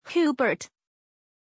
Aussprache von Hubert
pronunciation-hubert-zh.mp3